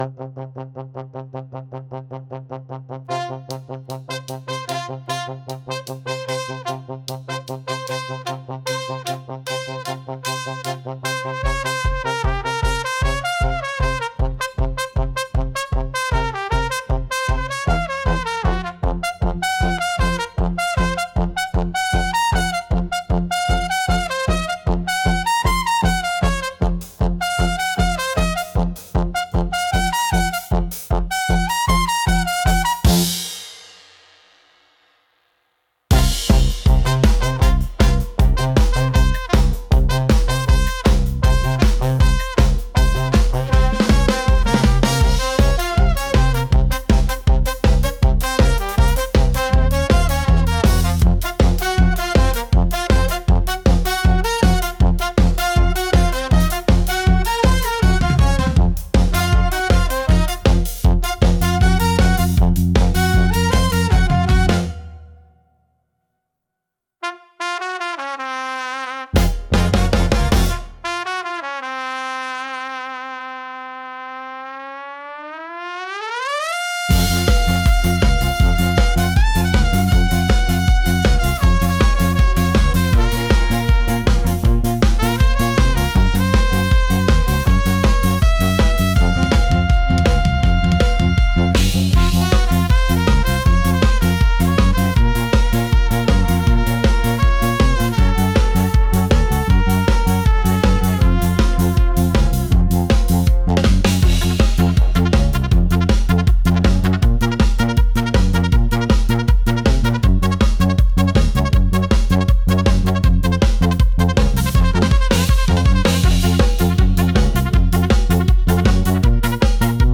クソゲーと揶揄されることもありますが、ゲーム中に流れるBGMを今聴くと……なかなかリズムがいい。